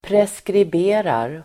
Ladda ner uttalet
Uttal: [preskrib'e:rar]